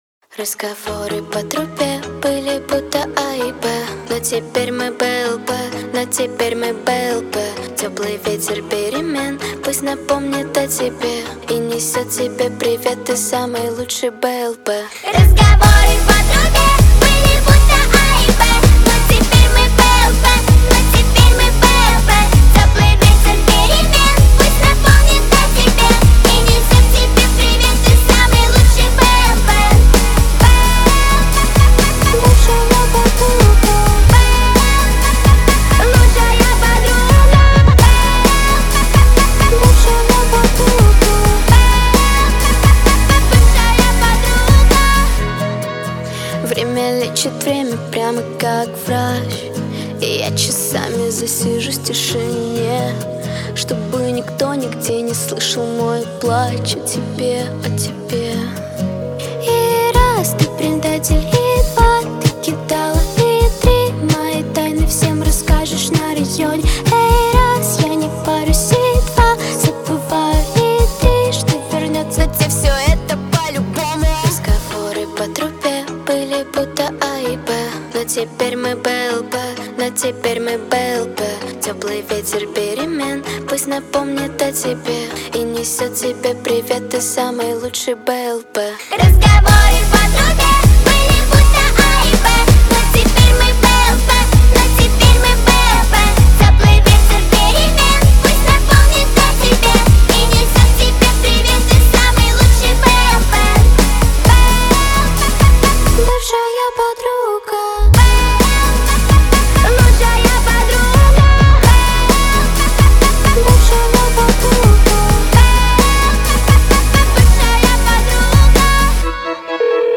Качество: 320 kbps, stereo
Поп музыка, Современная музыка